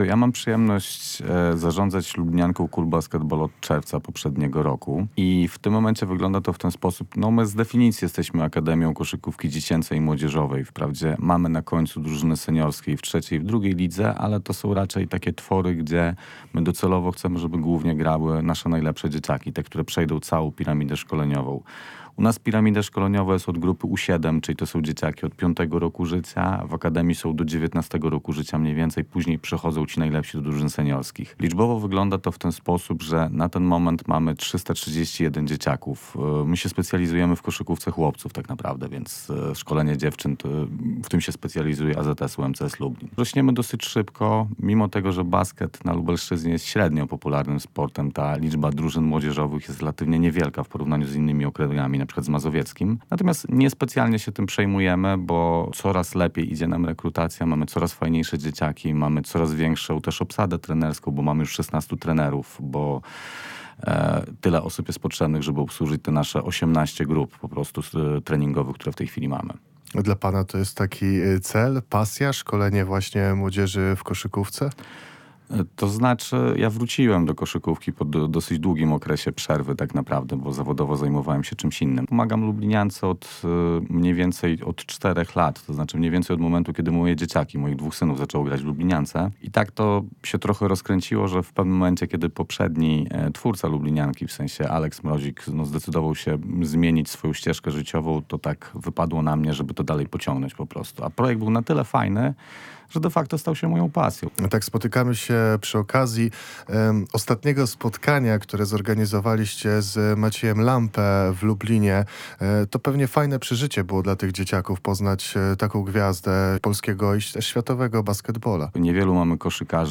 Cała rozmowa w materiale dźwiękowym: